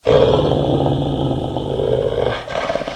growl1.ogg